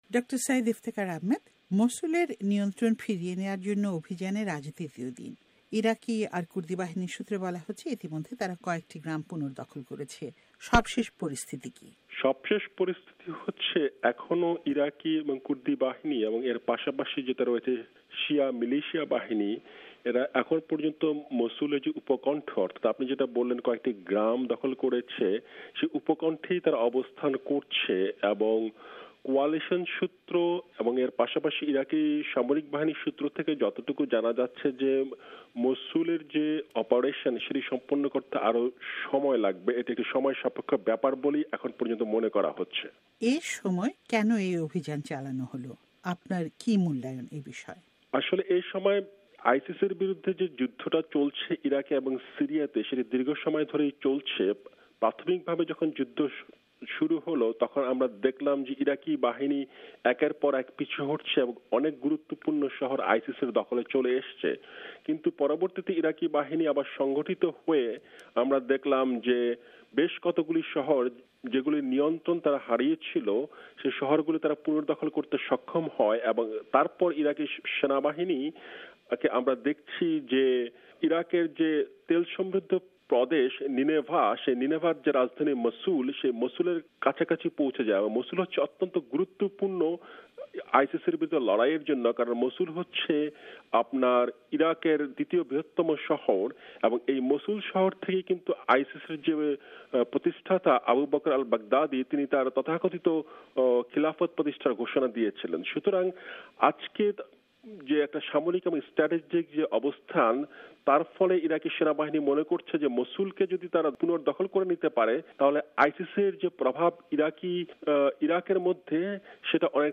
সাক্ষাৎকার